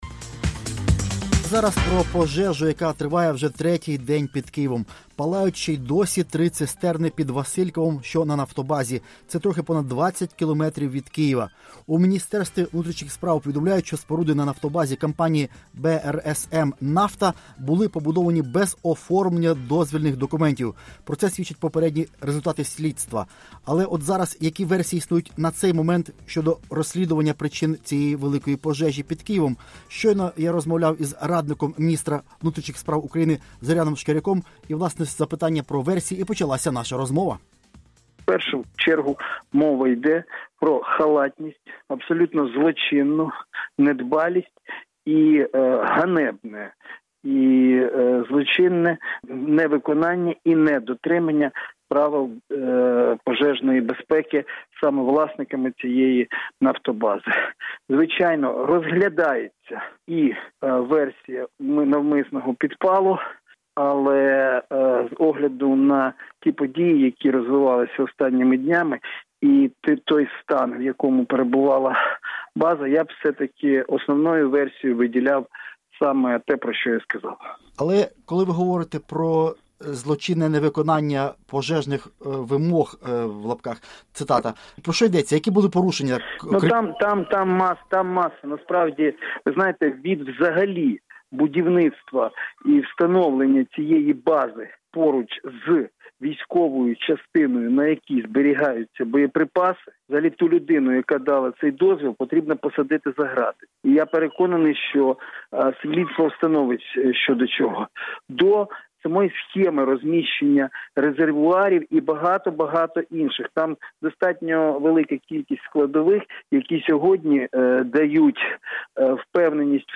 Радіо Свобода розмовляло з радником міністра внутрішніх справ України Зоряном Шкіряком про перебіг розслідування.